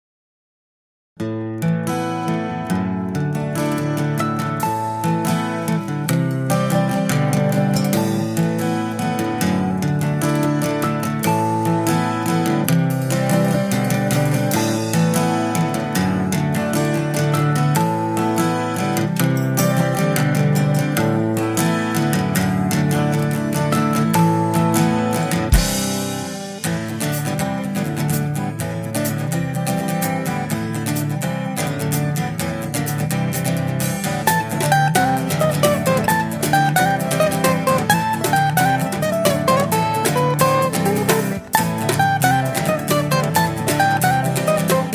Folk / Celta/ World Music